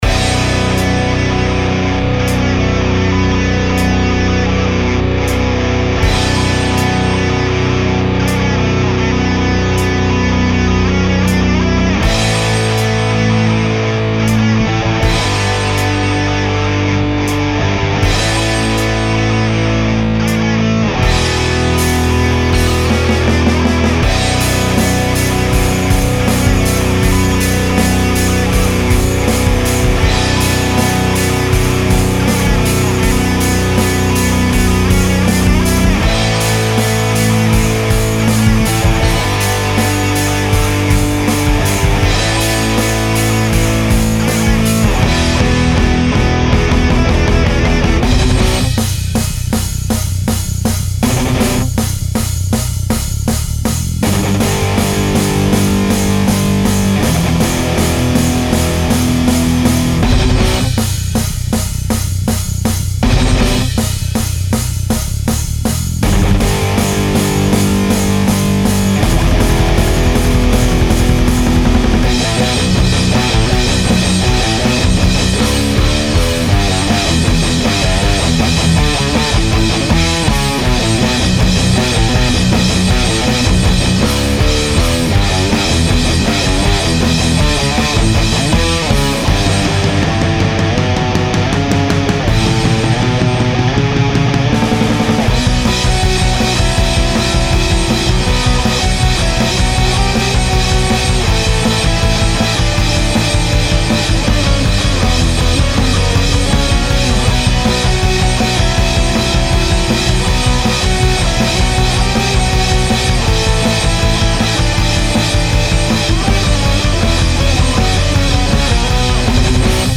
Записано на ibanez rg7321 и Warwick Corvette Standard.
ритм гитара глуховата, немного вяло сыграно